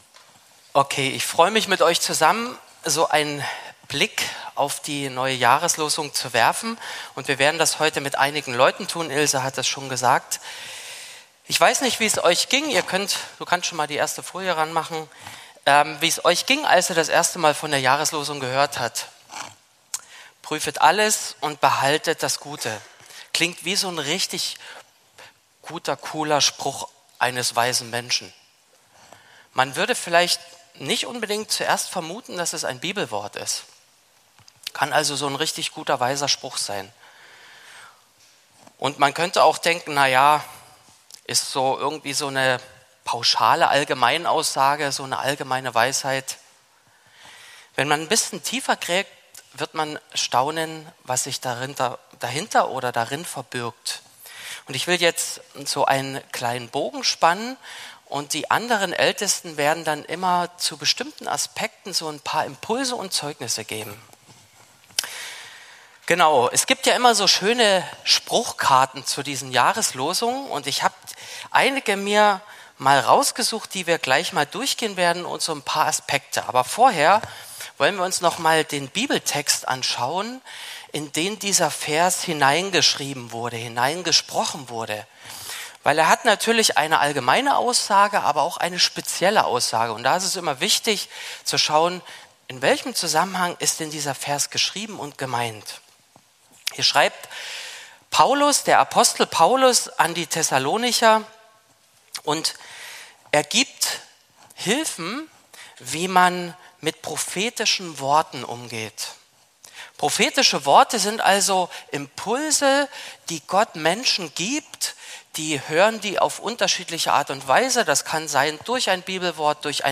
Die Predigt der Ältesten zu der Jahreslosung 2025